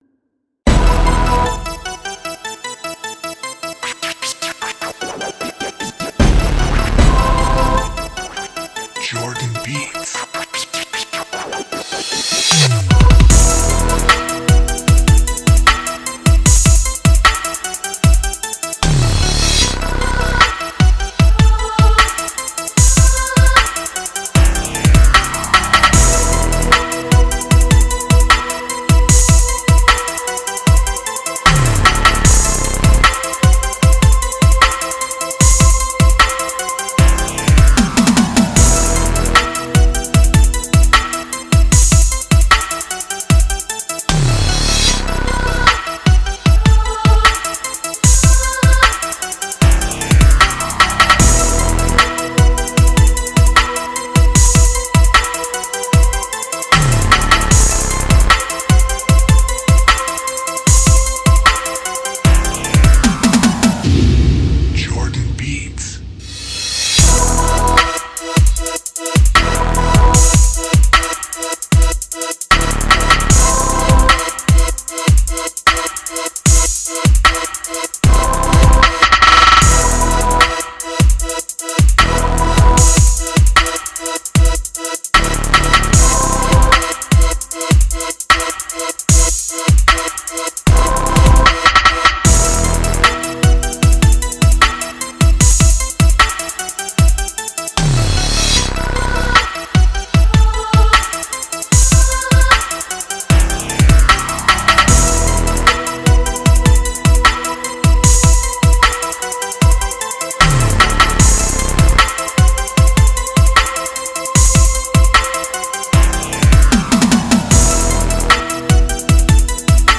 Hard_Organ_Choir_Rap_Beat_Synth_Organ_prod.mp3